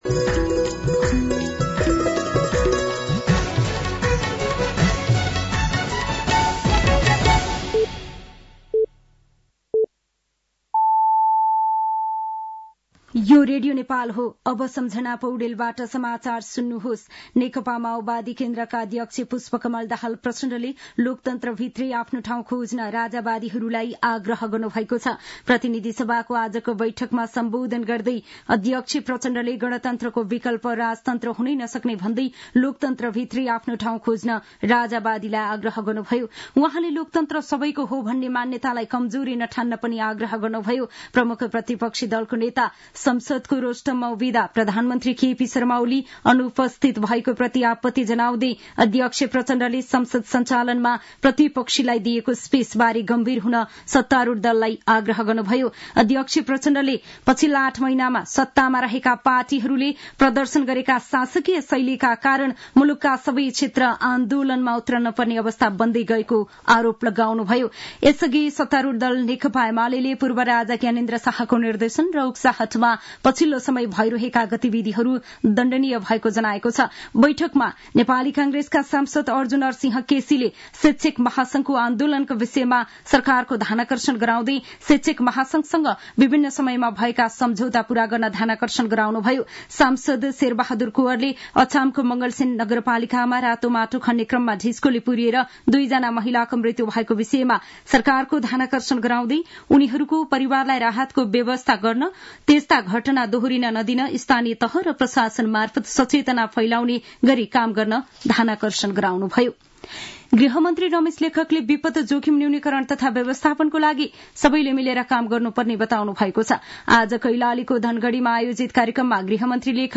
साँझ ५ बजेको नेपाली समाचार : २८ फागुन , २०८१